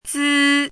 《孜》,孜的意思|孜的读音
zi1.mp3